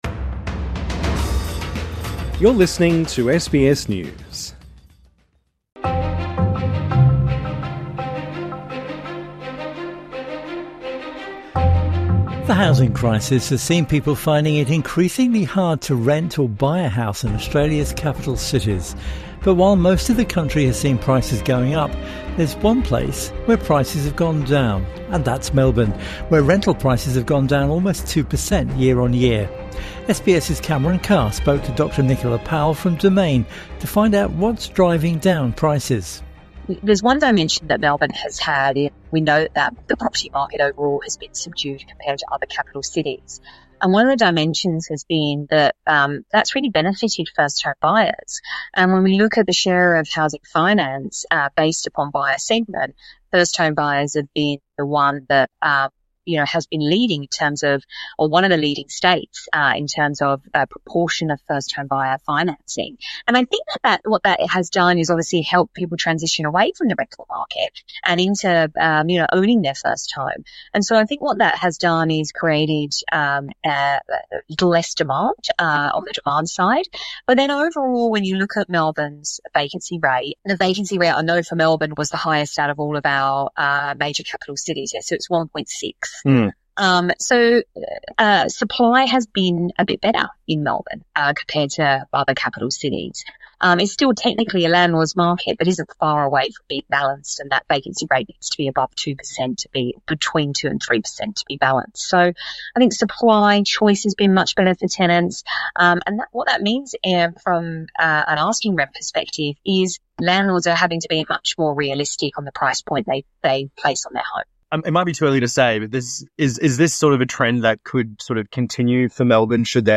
Weekend One on One